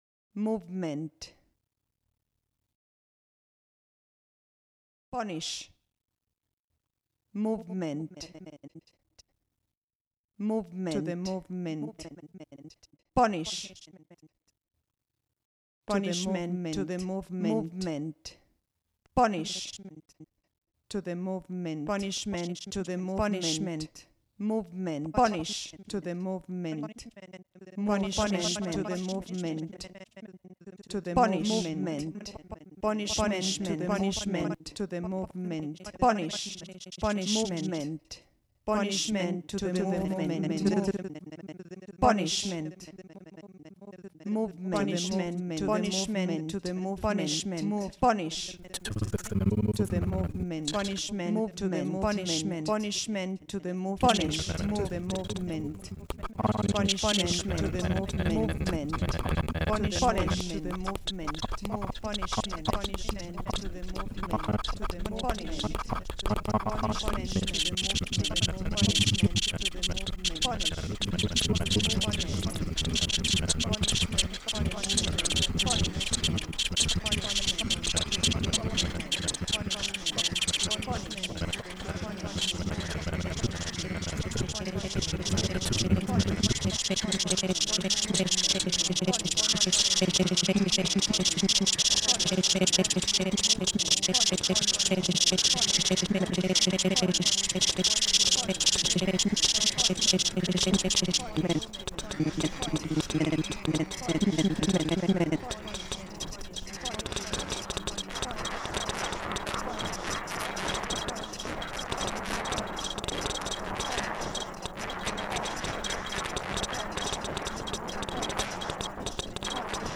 Sound design for the dance piece
Choreographers voice and electroacoustic sounds